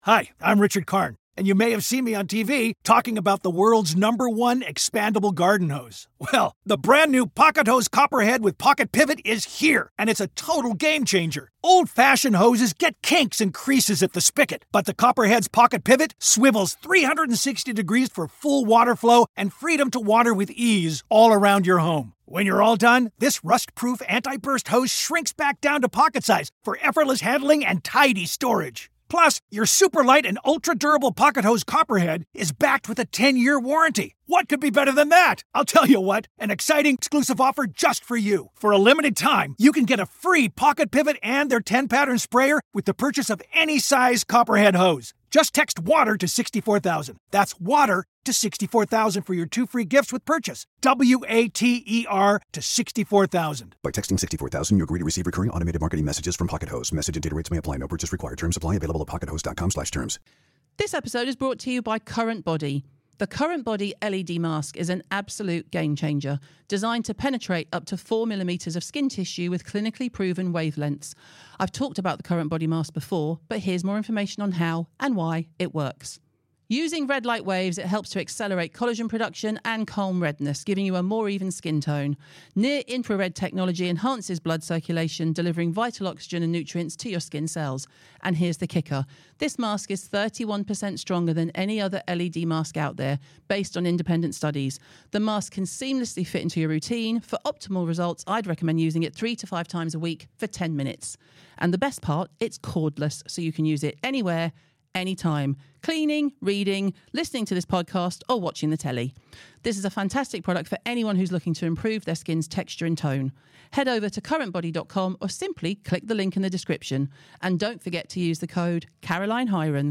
Join Caroline and Stacey as they answer all your questions. This week it's all about parenting, hoarders and marriage.